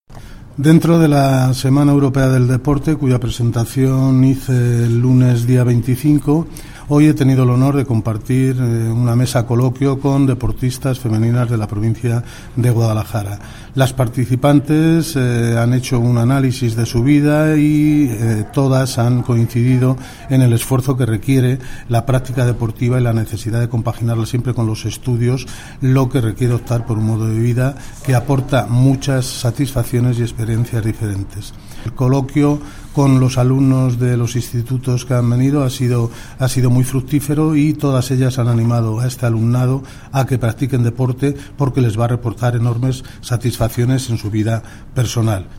Consejería de Educación, Cultura y Deportes Miércoles, 27 Septiembre 2017 - 2:30pm Dentro de la Semana Europea del Deporte hoy he tenido el honor de compartir un coloquio con deportistas femeninas de Guadalajara. Las participantes han hecho un análisis de su vida y han coincidido en el esfuerzo que requiere compaginar el deporte y los estudios lo que supone optar por un modelo de vida que aporta mucha satisfacciones y experiencias diferentes.